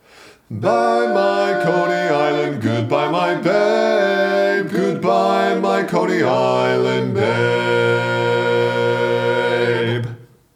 Other part 3: